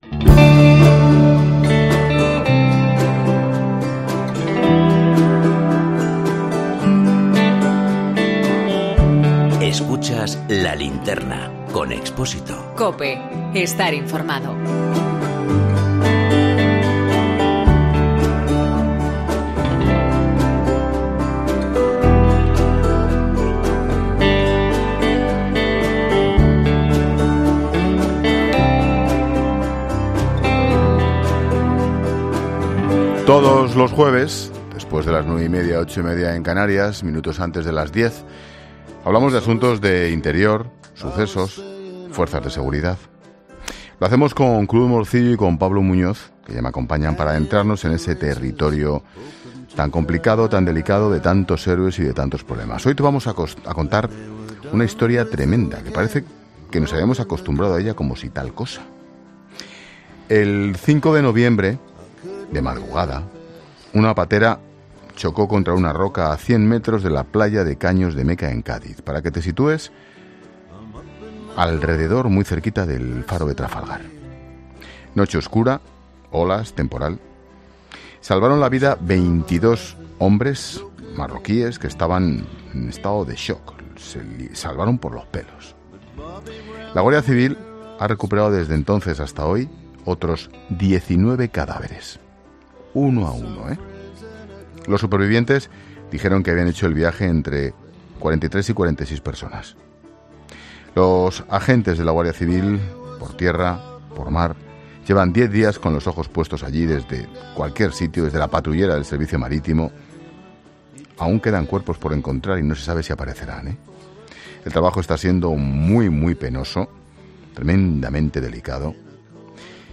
Ángel Expósito ha entrevistado en 'La Linterna'